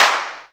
VEC3 Claps 010.wav